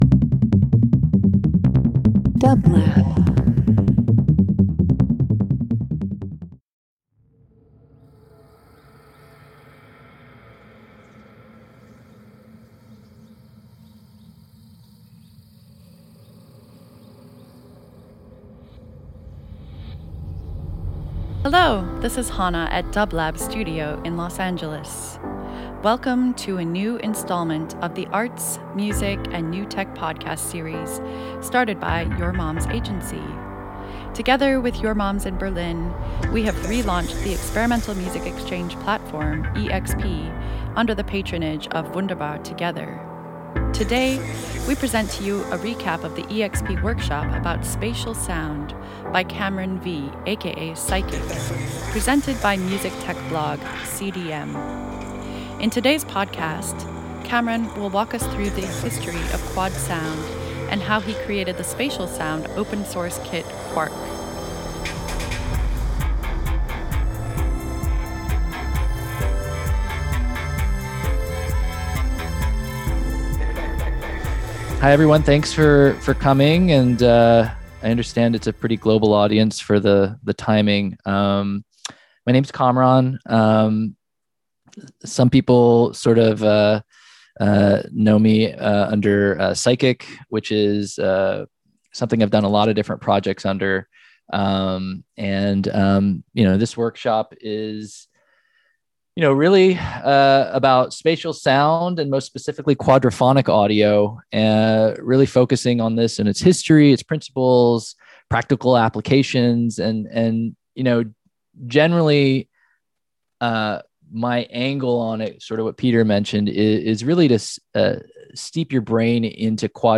Alternative Experimental Interview